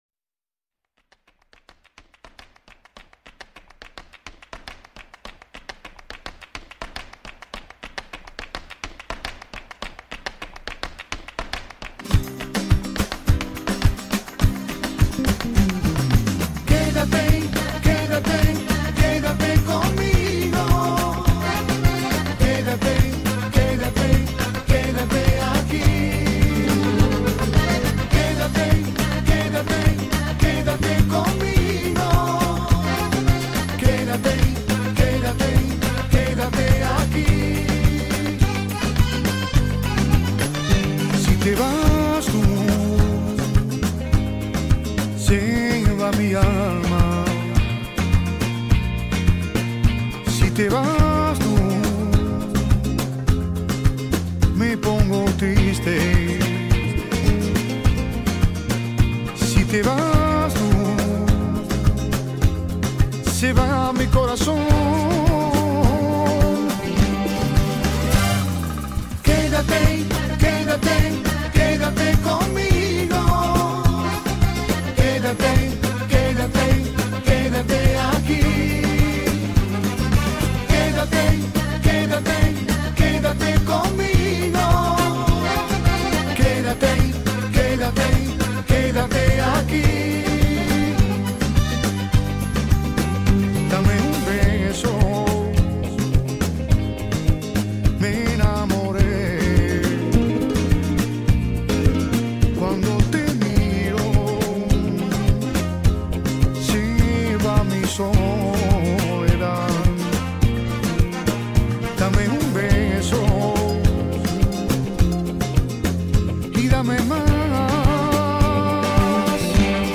Carpeta: musica arabe mp3
karaoke